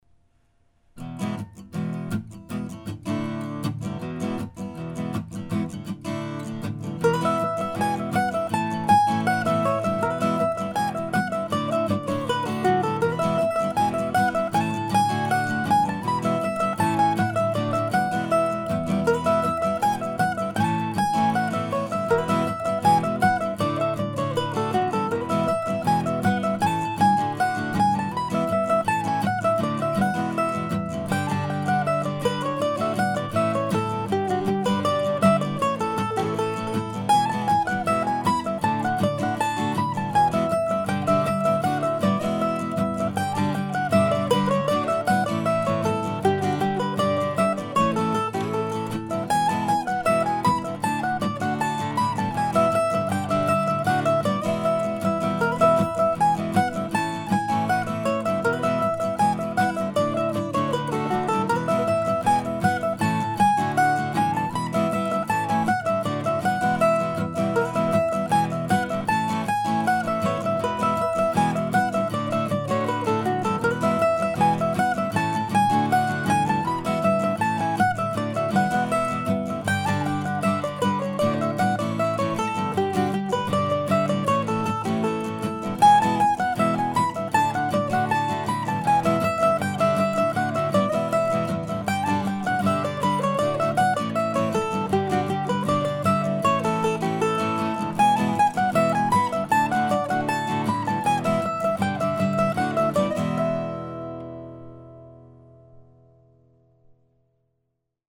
Hold That Thought ( mp3 ) ( pdf ) Here's a tune from March of 2008 that I recorded today as a slower reel.
I used a recently acquired old Gibson A Jr. mandolin, which has some intonation and tuning issues but sounds really good, to my ears, on this track.